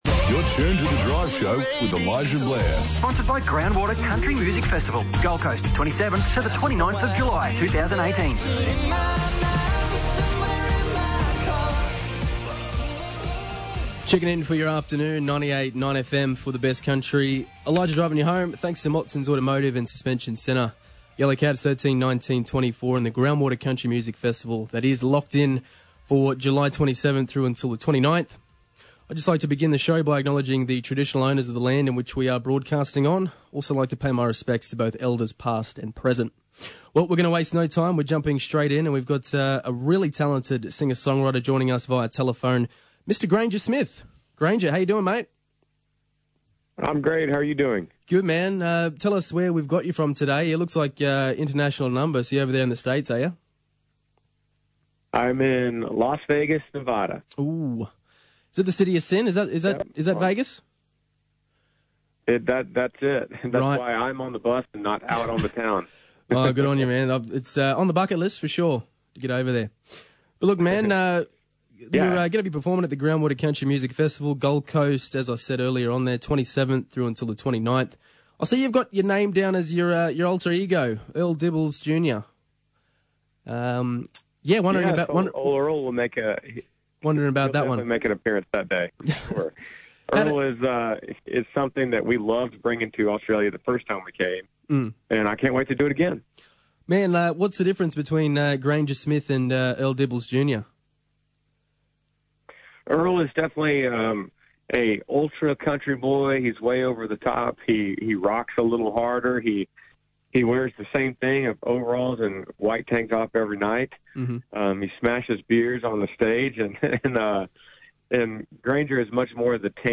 speaking with Granger Smith direct from Las Vegas